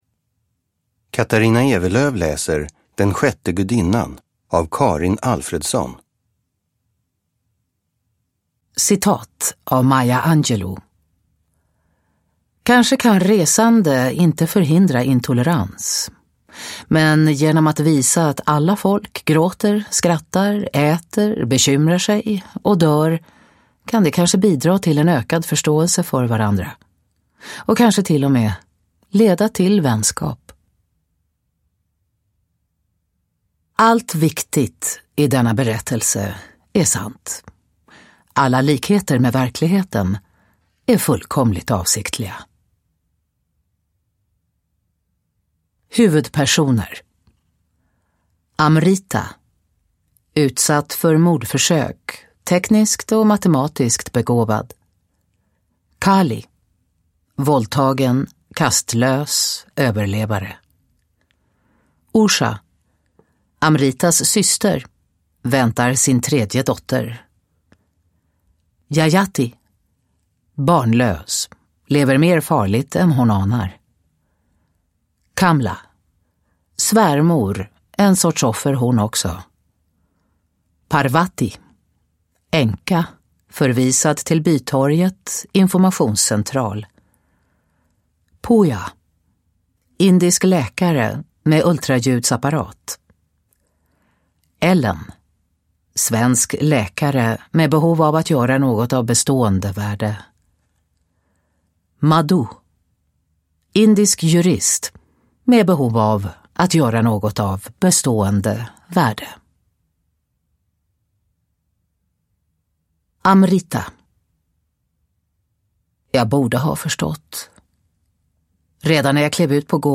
Den sjätte gudinnan – Ljudbok – Laddas ner
Uppläsare: Katarina Ewerlöf